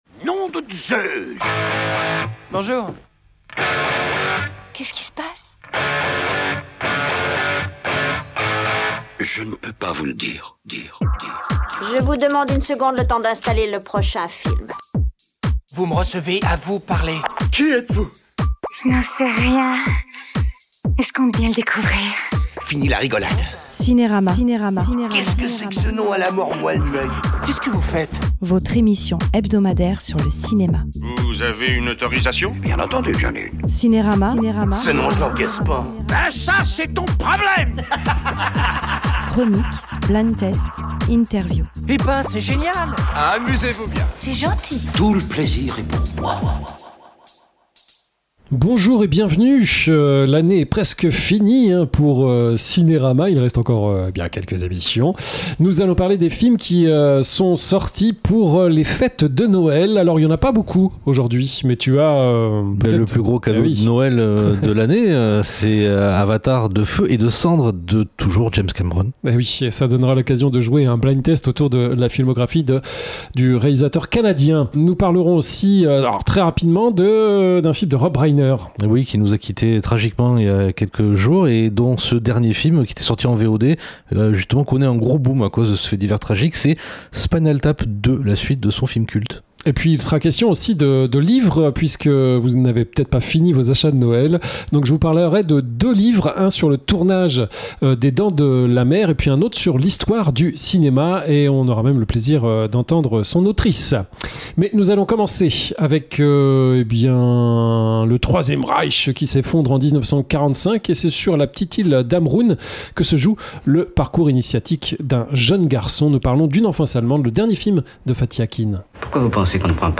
LE BLIND TESTComme chaque fois, pour la dernière émission, c’est un blind test sur les films sortis durant l’année avec non pas 5 mais 10 extraits.
L’INTERVIEW